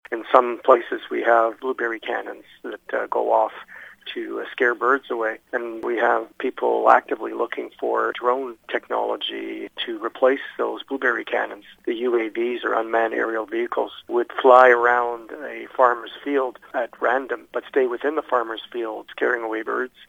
He gives an example of some of the new research being done.